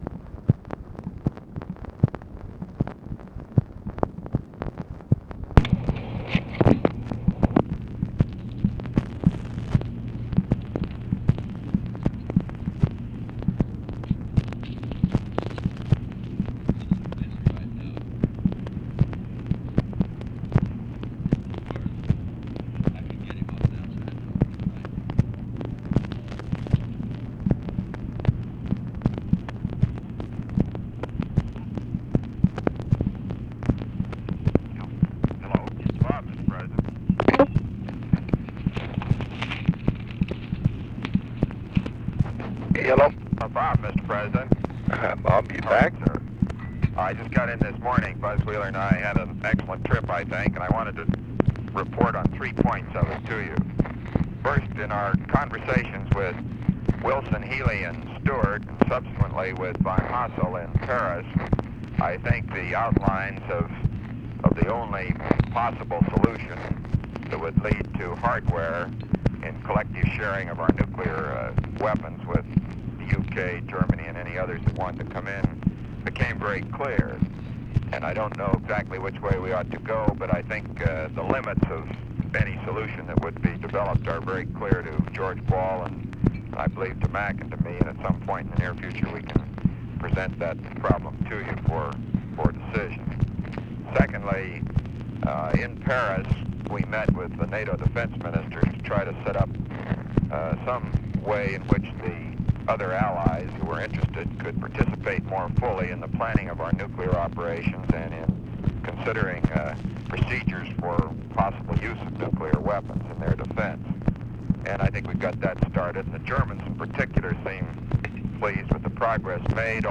Conversation with ROBERT MCNAMARA, November 30, 1965
Secret White House Tapes